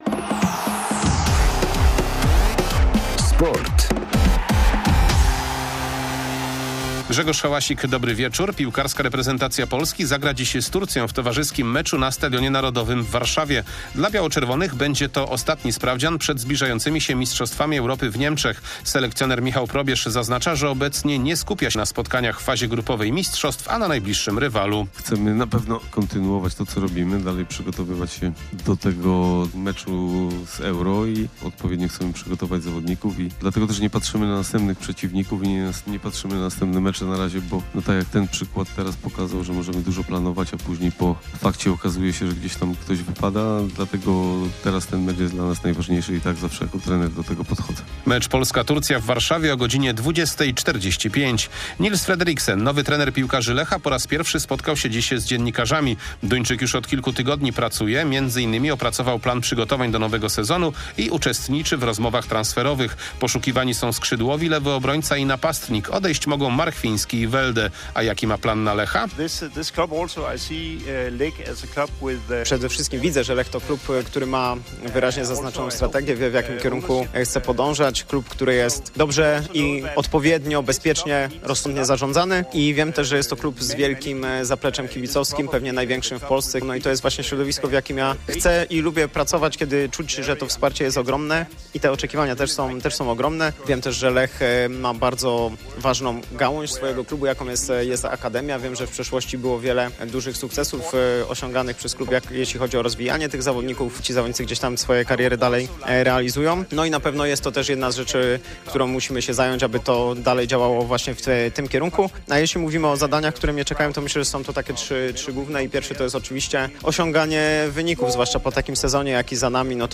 10.06.2024 SERWIS SPORTOWY GODZ. 19:05